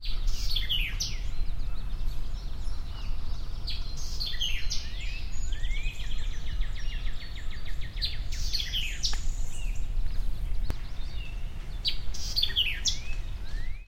This is a brief excerpt of the second WEVI on Whitehead Rd. Its song is similar in gesture to Bird No. 1’s 1st song